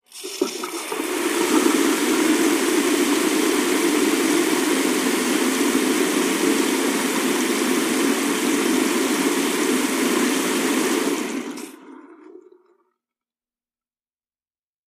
fo_sink_runwater_fast_02hpx
Bathroom sink faucet runs at slow, medium and fast flows.